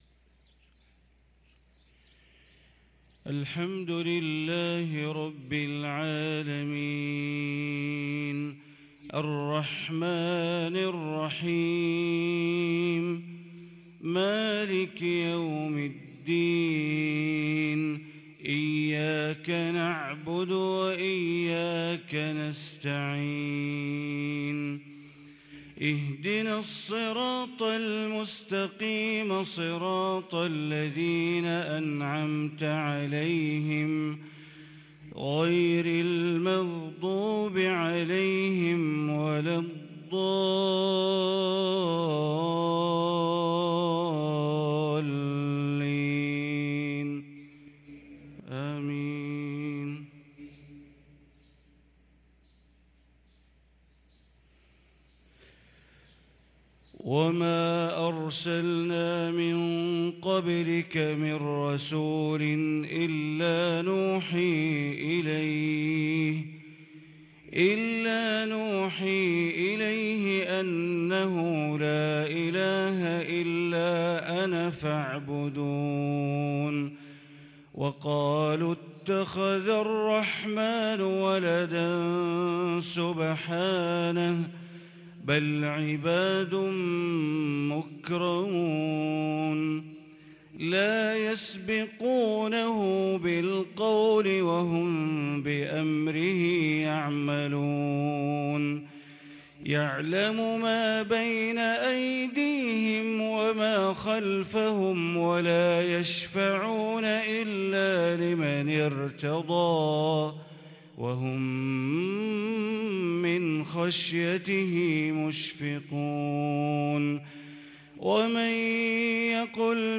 صلاة الفجر للقارئ بندر بليلة 10 صفر 1444 هـ
تِلَاوَات الْحَرَمَيْن .